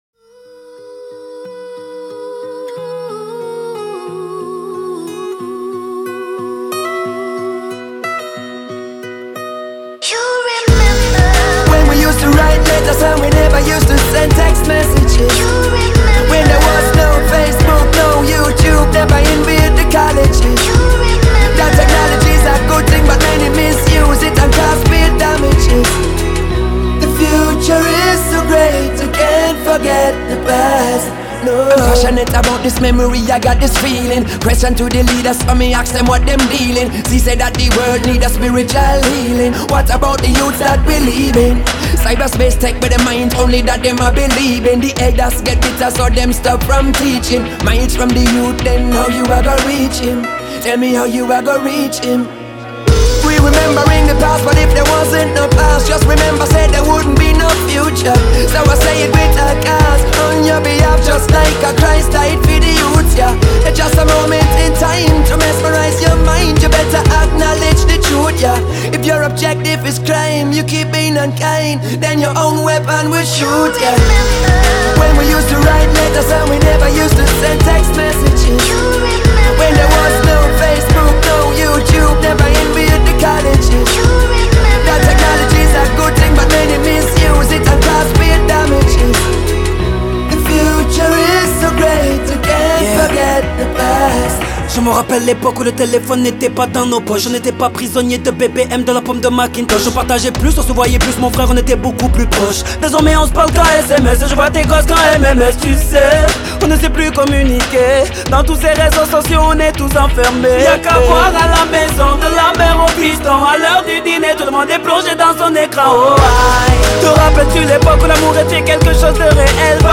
Hip-hop
Reggae crossover